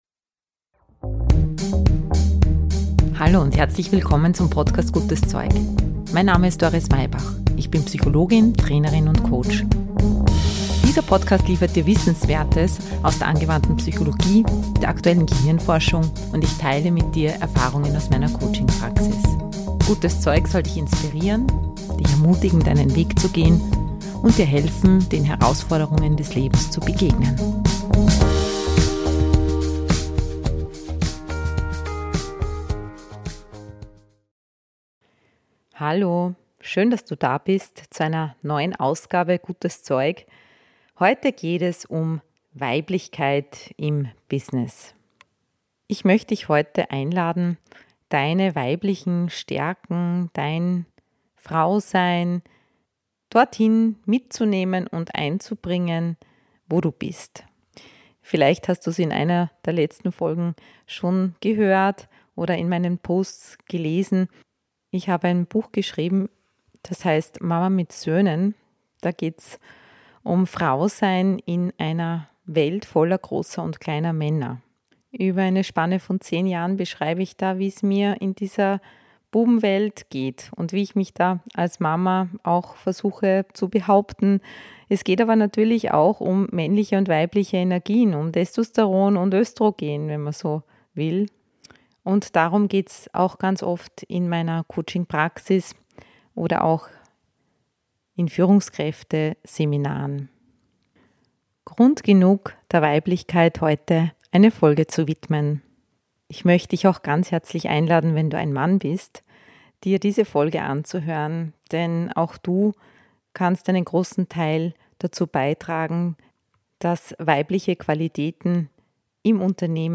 Am Ende lese ich dir noch eine Geschichte vor.